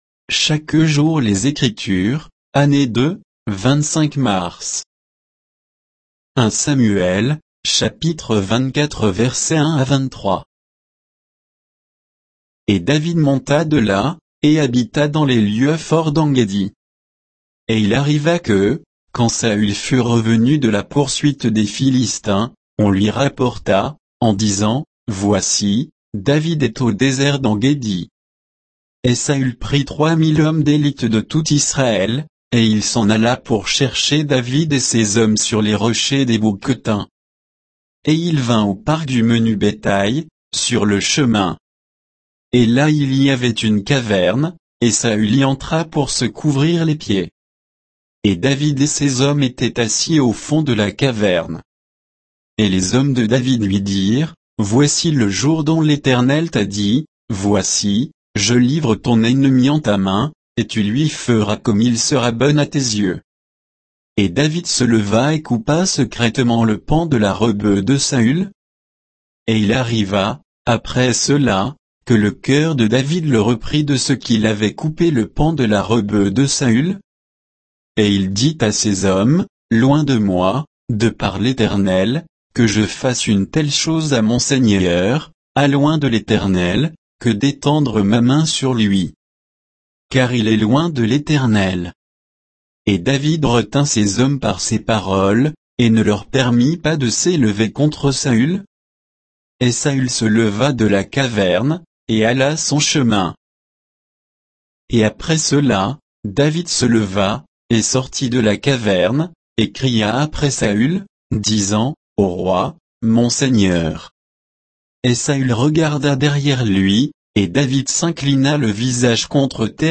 Méditation quoditienne de Chaque jour les Écritures sur 1 Samuel 24